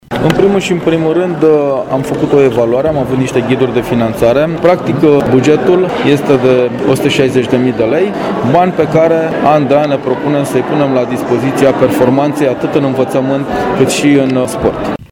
Premierea a fost făcută de președintele Consiliului Județean Brașov, Adrian Veștea: